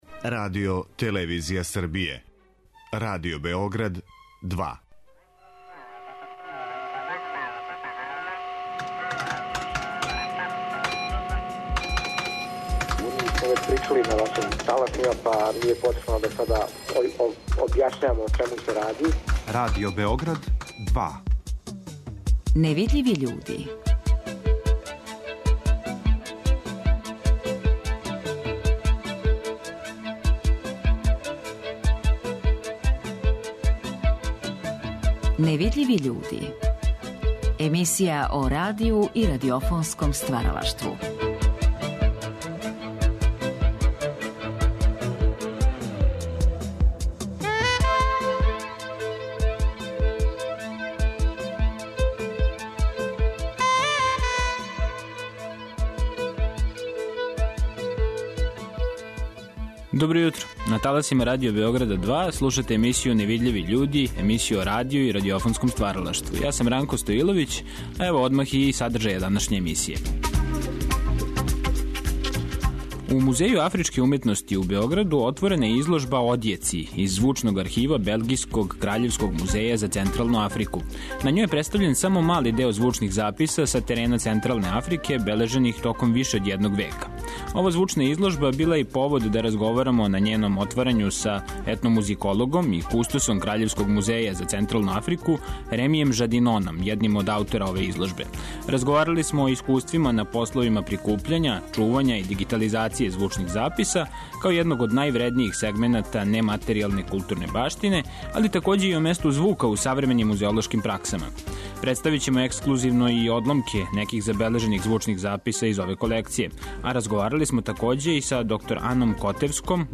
Представићемо ексклузивно и фрагменте неких забележених звучних записа из ове колекције.